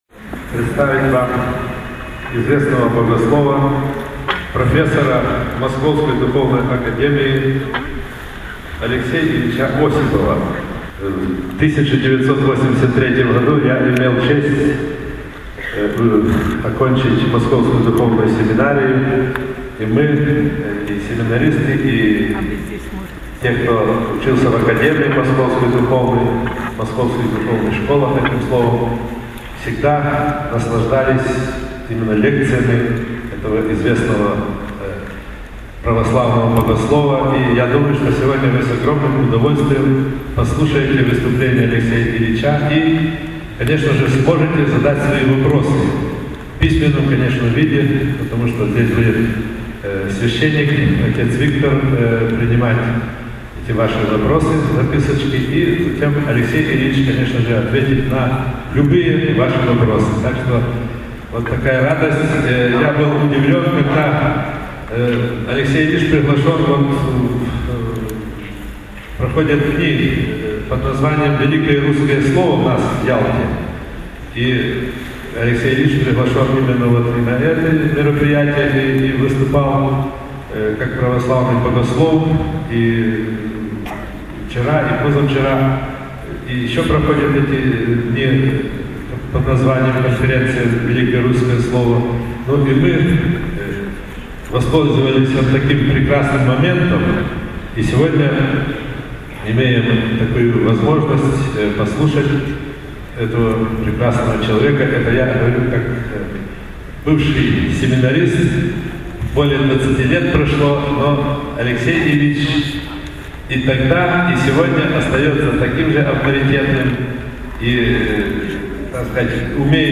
Выступление в КЦ «Юбилейный» (Ялта. Украина, 2008)
Видеолекции протоиерея Алексея Осипова